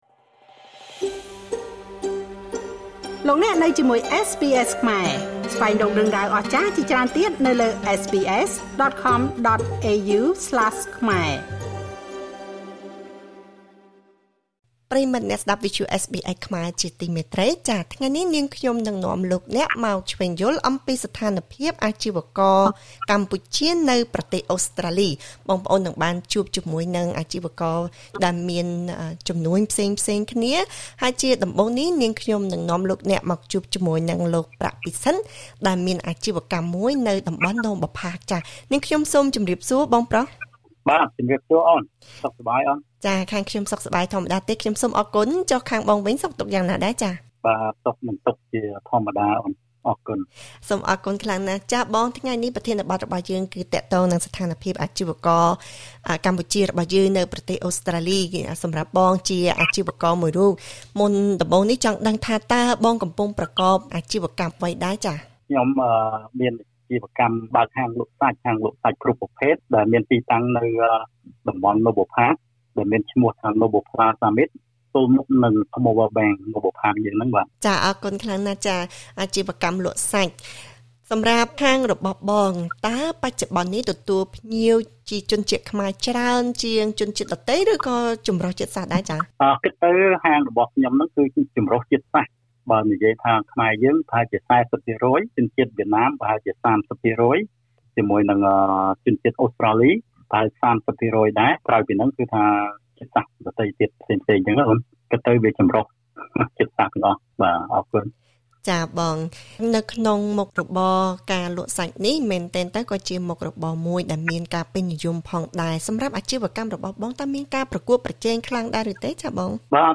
ប្រជាពលរដ្ឋអូស្រ្តាលីហាក់បីដូចជាមានក្តីព្រួយបារម្ភក្នុងការប្រកបអាជីវកម្មនានា នៅពេលមានការប្រែប្រួលមួយចំនួនចំពោះសេដ្ឋកិច្ច ជាពិសេសផលប៉ះពាល់បន្ទាប់កូវីដ និងការកើនឡើងនៃថ្លៃចំណាយការរស់នៅ។ តើអាជីវករពិតជាទទួលរងផលប៉ះពាល់ខ្លាំងឬទេចំពោះបញ្ហានេះ? សូមស្តាប់បទសម្ភាសជាមួយអាជីវករកម្ពុជាអូស្រ្តាលីពីររូប ដែលកំពុងប្រកបអាជីវកម្មនៅទីក្រុងមែលប៊ន។